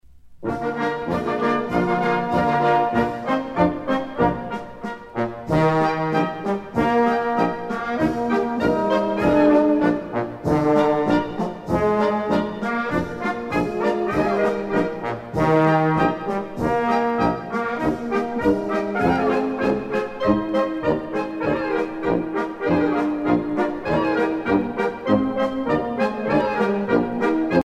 danse : polka ;
Pièce musicale éditée